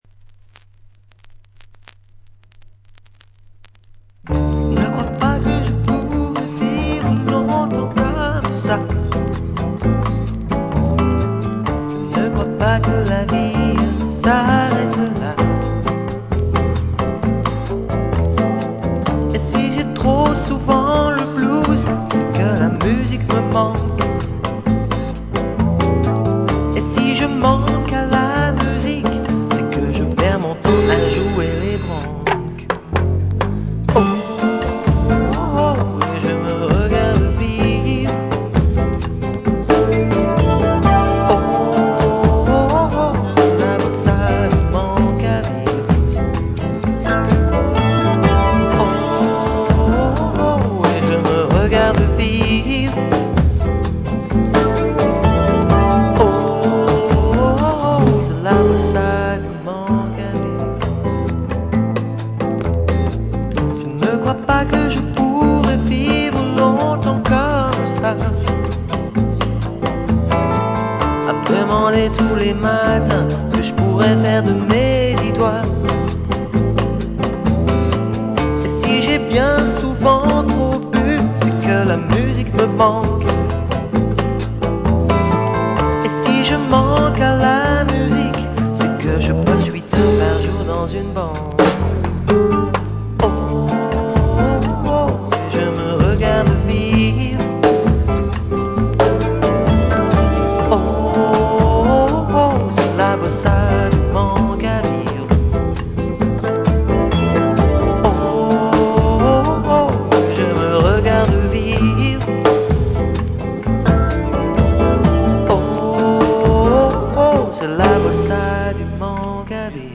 Certains enregistrements sont tirées de cassettes, certains de bandes démos et d'autres de vinyls.
La qualité est donc moyenne.